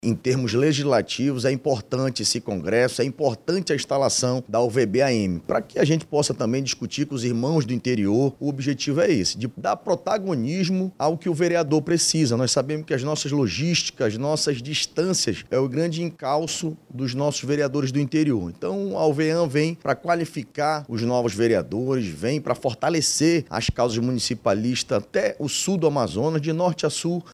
O vereador Diego Afonso, do União Brasil, destaca a importância da discutir as causas municipalistas, além da interligação entre parlamentares da capital e do interior do Estado.